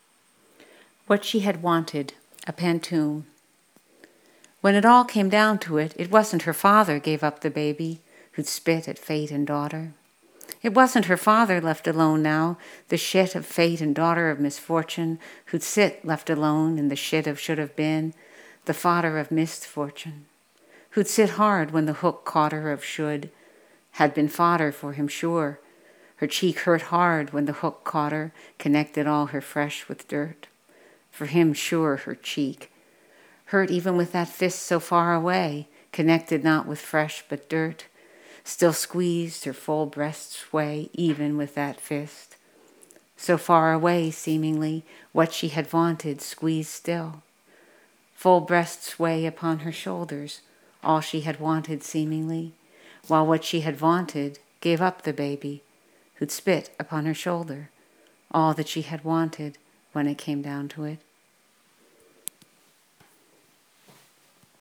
For Real Toads, I added an audio recording (not so great) but I think a reading illuminates a poem like this since the pauses are taken in odd places.  In the light, note that all the pauses are based on punctuation and not line breaks.